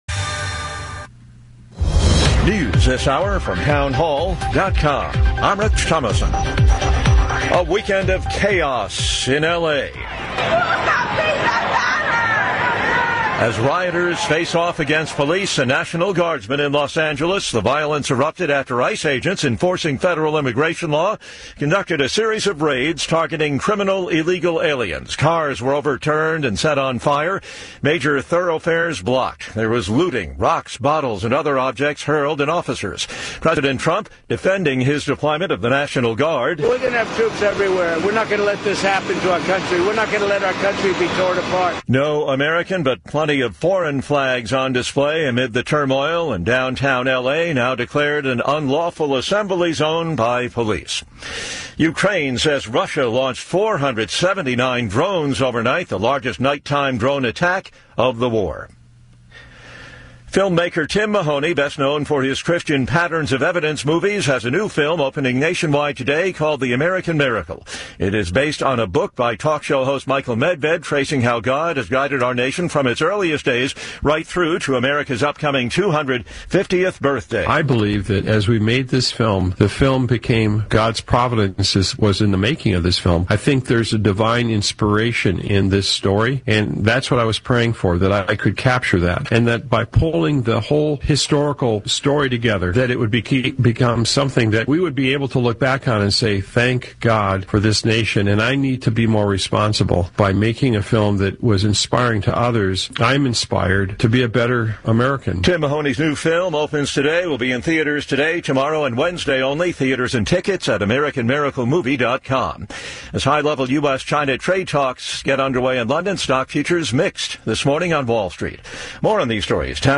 guest-host Del. Nino Mangione discuss the latest in the ICE LA riots, Kilmar Albrego Garcia being brought back to US to face charges, the latest on the BBB in the Senate and more.